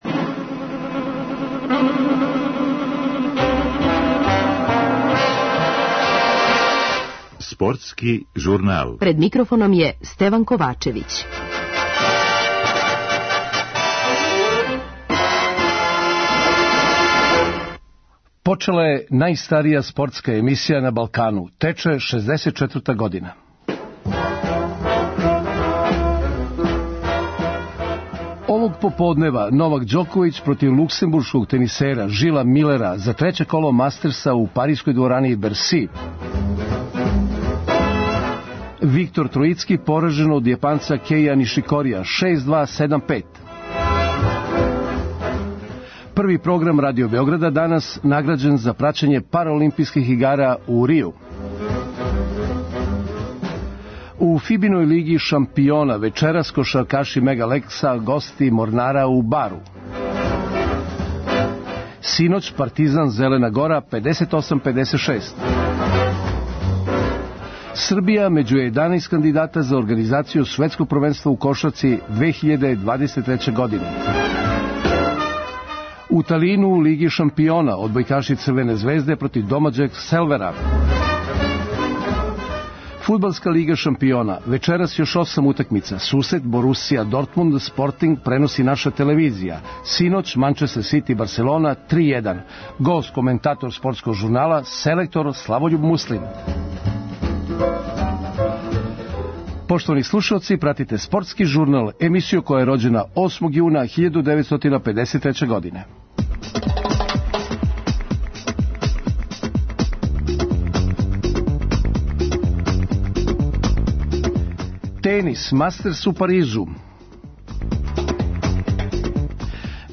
Гост коментатор Спортског журнала - селектор Славољуб Муслин.